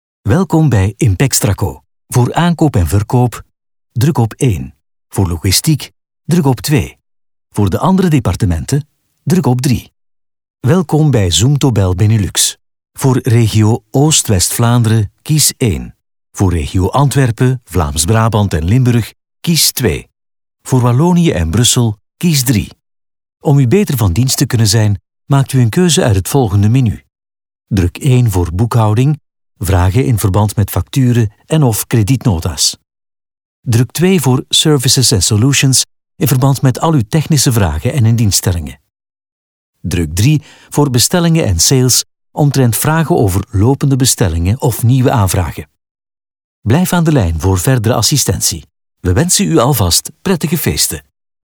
Natural, Reliable, Commercial, Versatile, Warm
Telephony